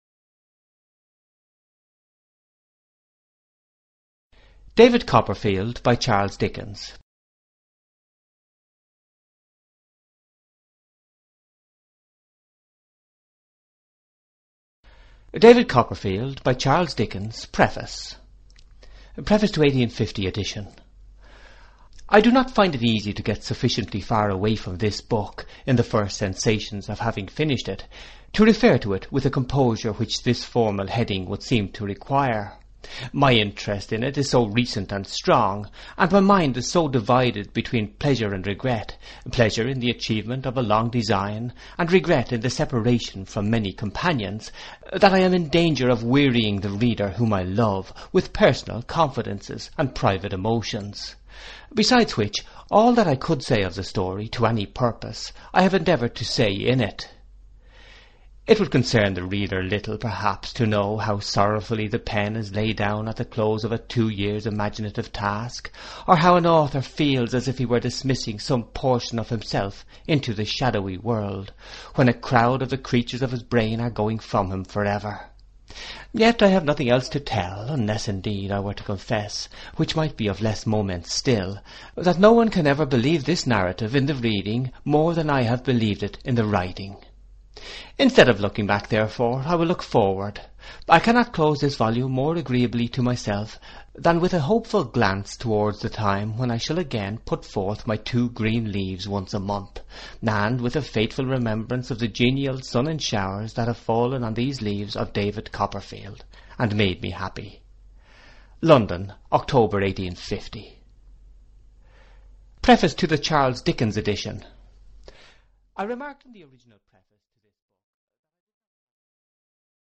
Audiobook - Charles Dickens, The Complete Novels, Volume 2